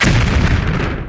laserBang2.ogg